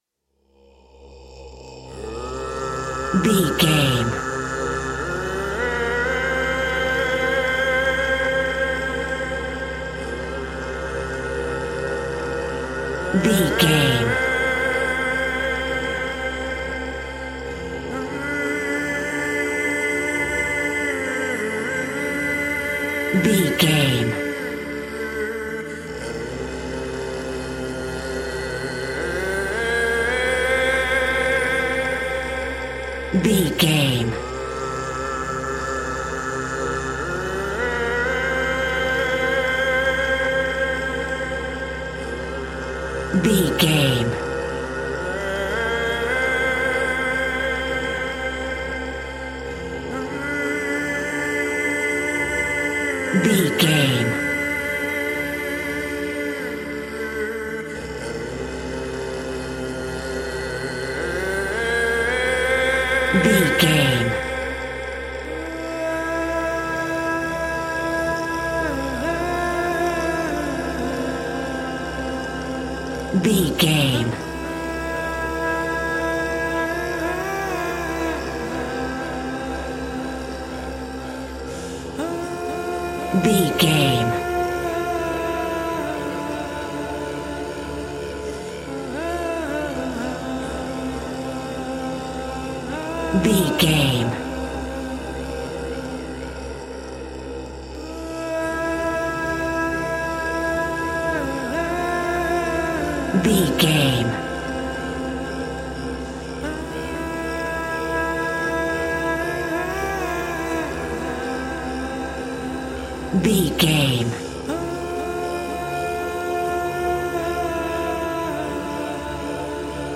Ionian/Major
groovy
vocal